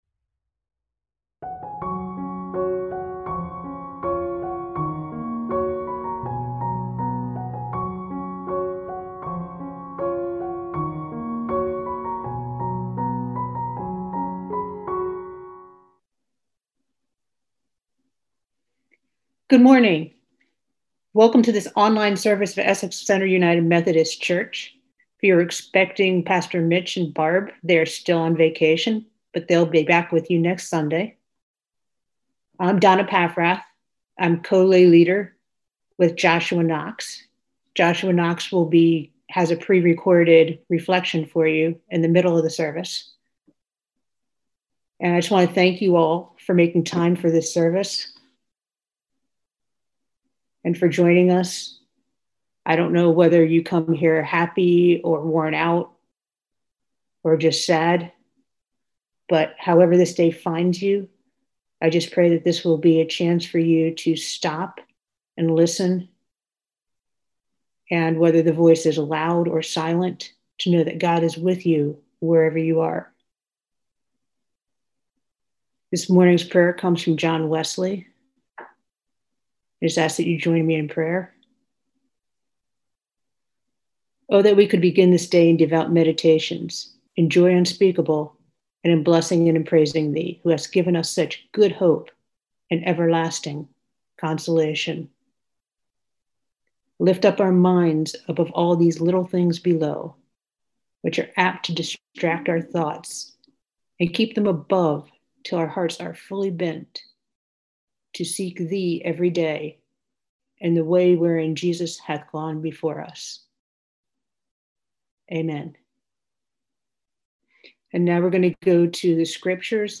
We held virtual worship on Sunday, August 23, 2020.